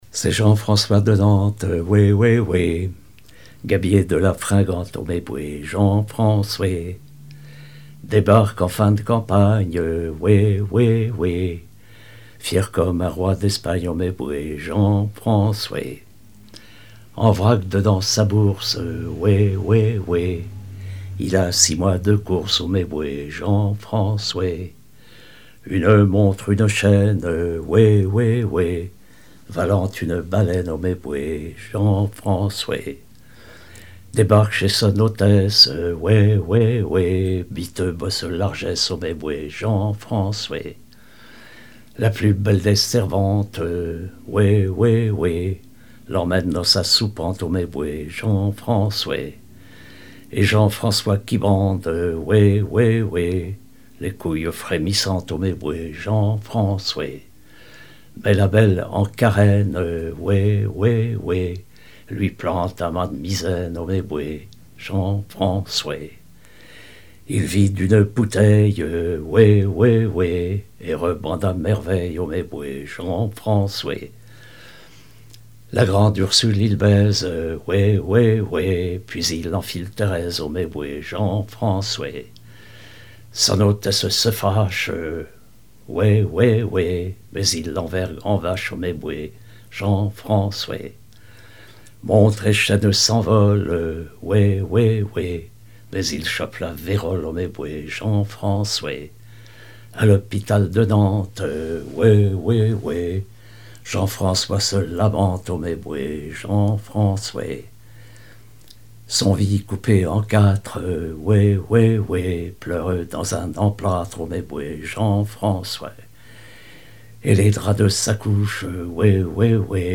Mémoires et Patrimoines vivants - RaddO est une base de données d'archives iconographiques et sonores.
Genre laisse
chansons maritimes et paillardes
Pièce musicale inédite